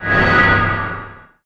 Magic_SpellEnergyBall06.wav